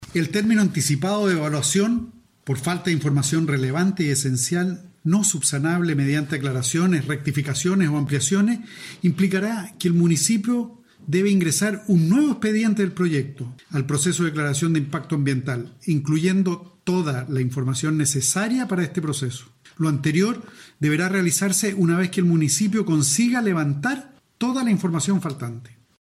Al respecto, el Intendente, Carlos Geisse, explicó acerca de este término anticipado de la evaluación, señalando que el municipio deberá presentar un nuevo proyecto de declaración, ingresando la información faltante.